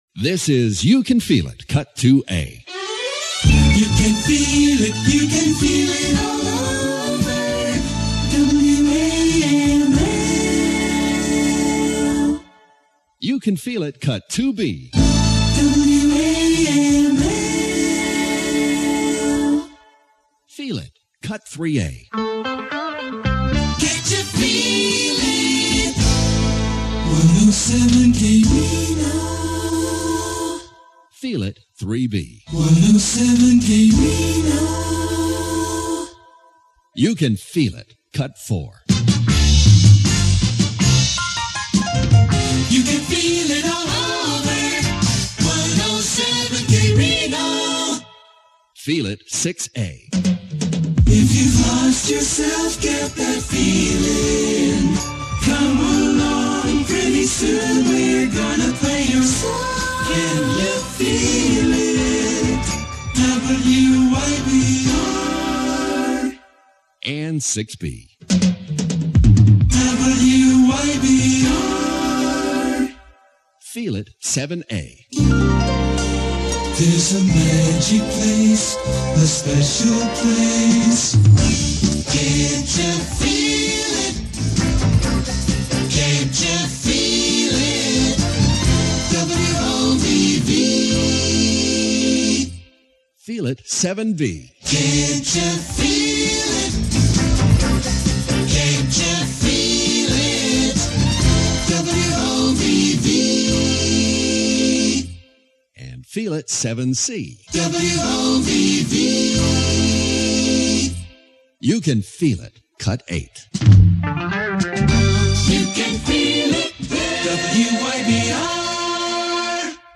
C'est très, très américain !...